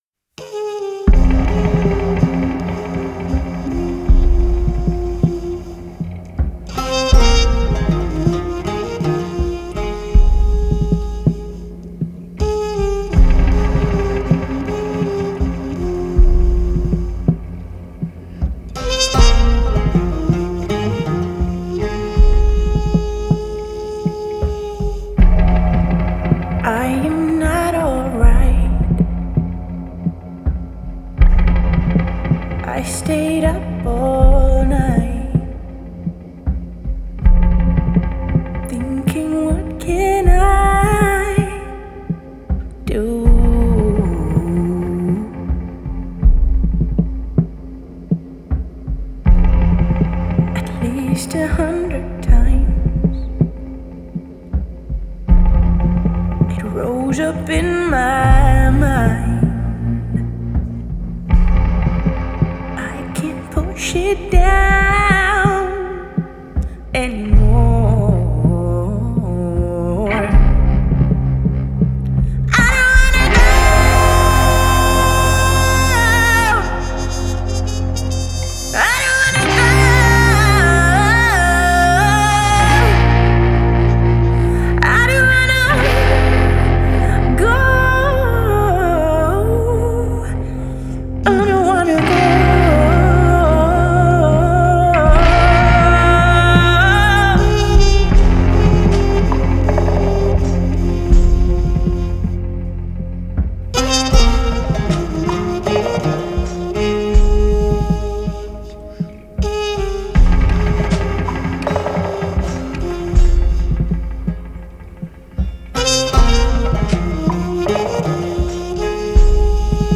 Genre: Indie Pop, Female Vocal, Experimental